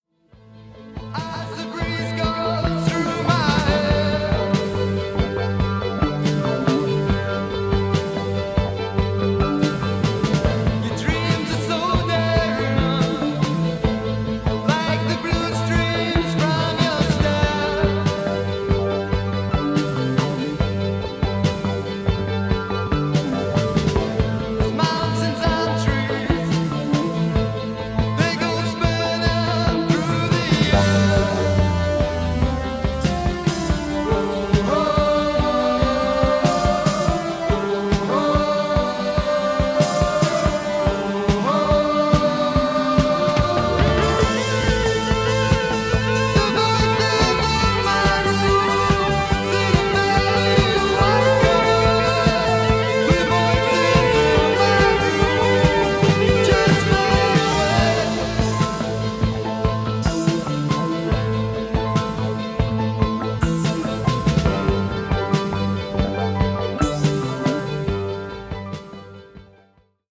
futurist and early electronic music
guitar/synth
keyboards